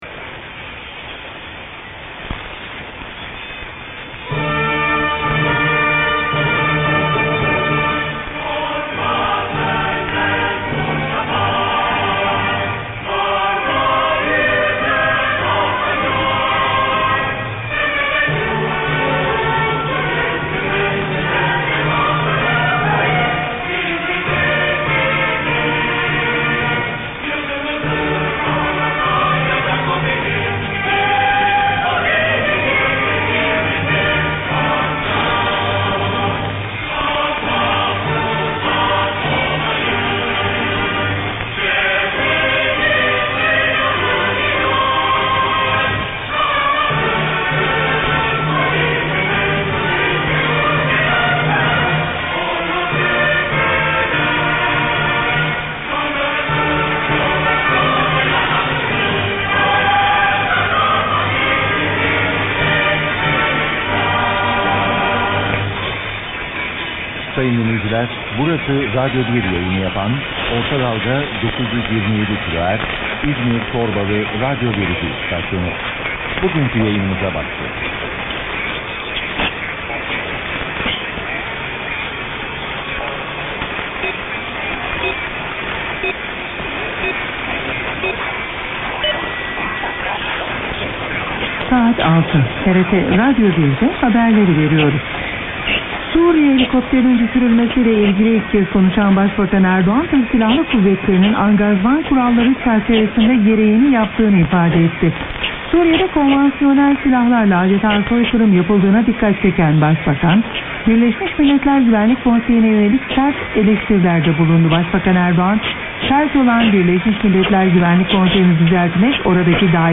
UNID SIGN ON 927 AT 0300:
I have been hearing a sign on at 0300 on the perfectly clear 927 these days.